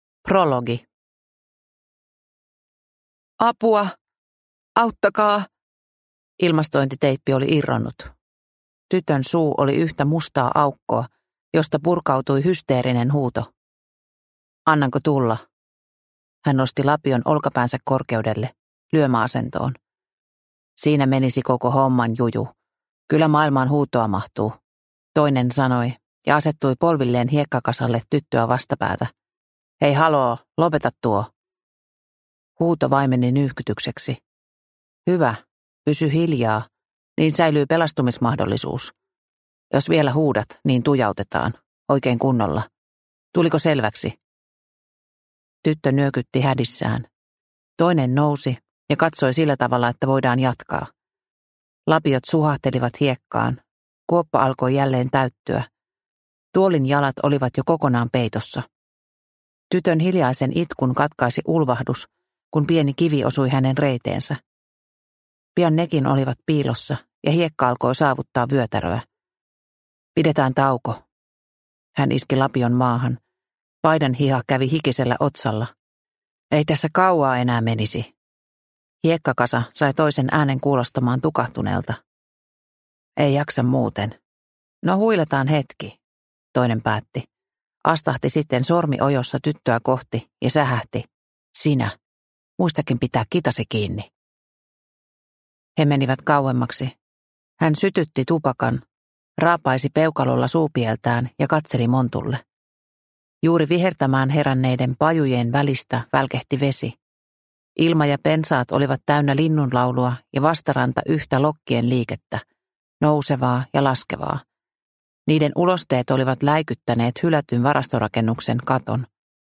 Vallilan murhammammat – Ljudbok – Laddas ner